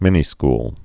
(mĭnē-skl)